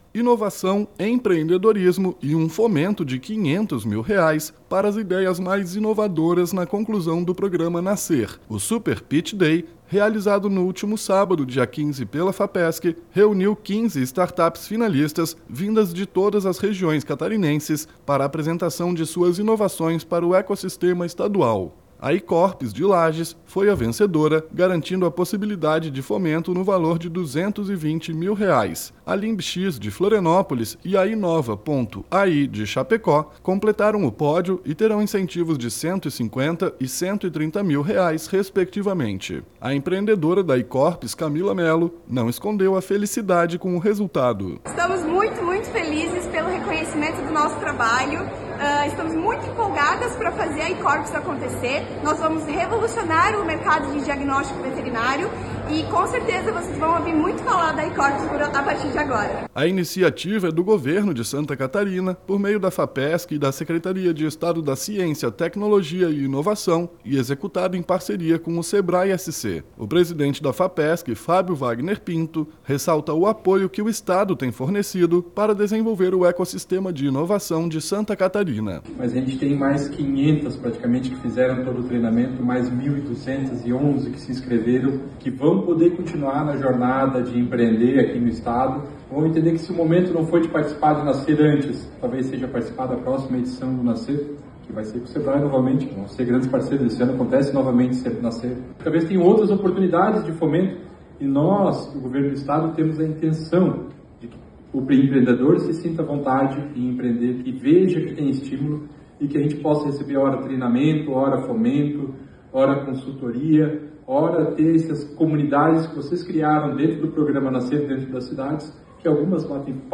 O presidente da Fapesc, Fábio Wagner Pinto, ressalta o apoio que o Estado tem fornecido para desenvolver o ecossistema de inovação em Santa Catarina:
Repórter